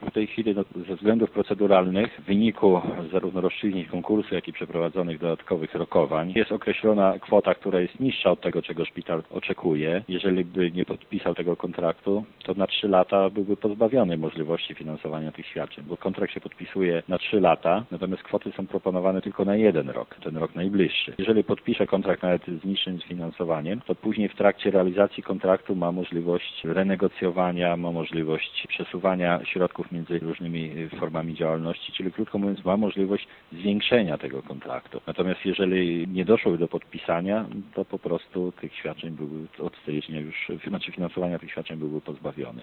Zdaniem przewodniczącego konwentu starosty lubelskiego Pawła Pikuli to jedyne rozwiązanie w obecnej sytuacji: